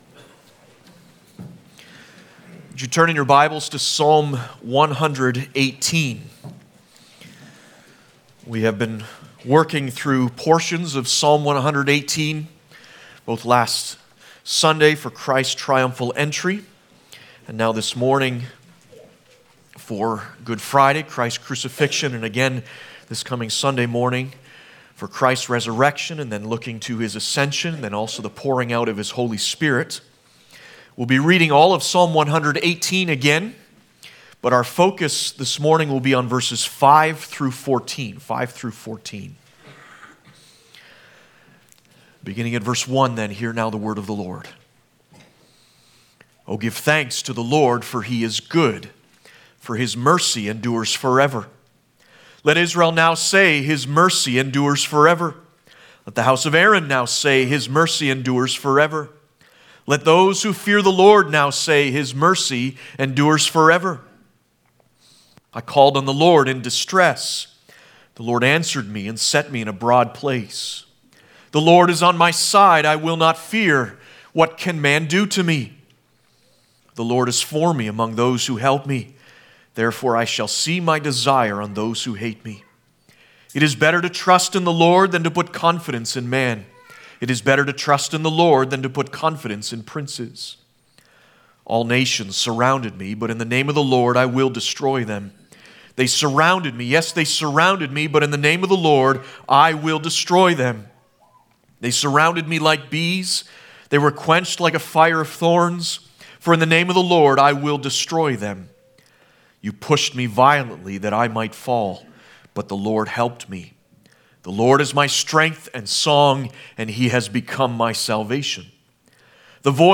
Passage: Psalm 118:5-14 Service Type: Good Friday